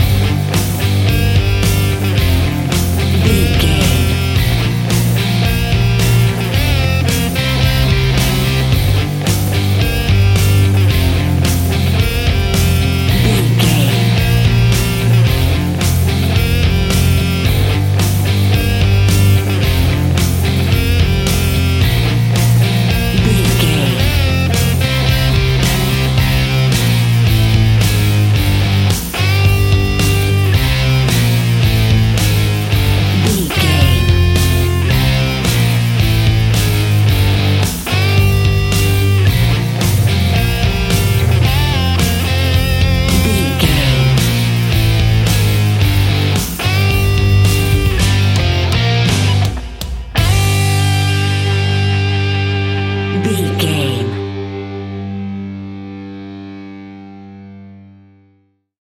Epic / Action
Aeolian/Minor
hard rock
blues rock
distortion
instrumentals
rock guitars
Rock Bass
heavy drums
distorted guitars
hammond organ